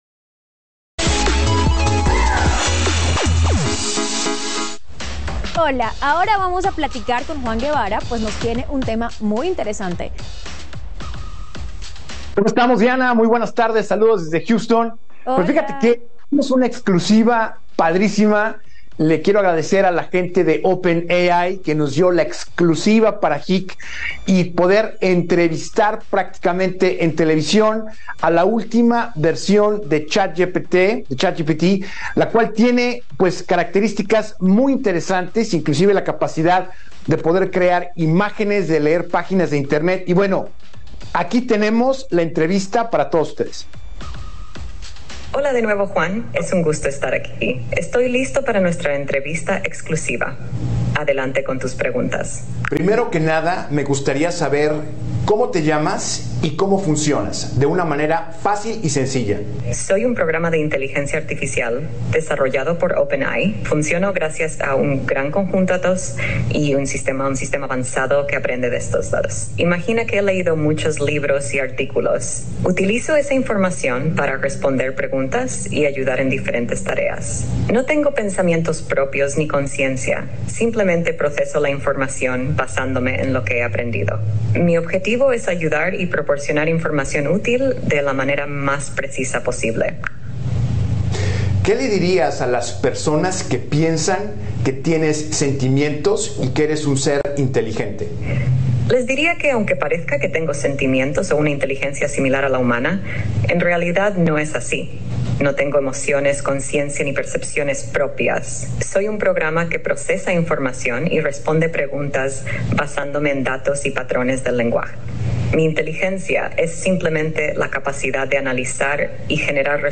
Entrevista Exclusiva con ChatGPT: Revelaciones Únicas sobre Inteligencia Artificial ( Audio )